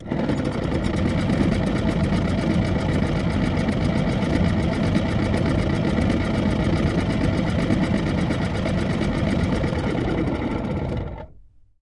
沃尔沃加热器 " 沃尔沃马达 6
描述：一辆老沃尔沃旅行车的加热器旋转起来，运行，然后停止。 它非常明显，而且，坏了。 2010年9月用Zoom H4录制的。 没有添加任何处理。
Tag: 加热器 电动机 旋转 沃尔沃 抱怨